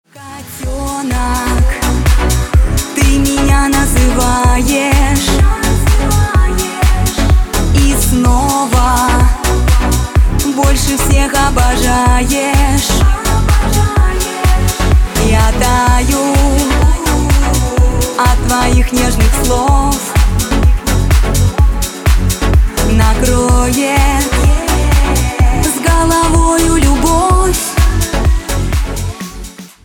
• Качество: 192, Stereo
женский вокал
Dance Pop
попса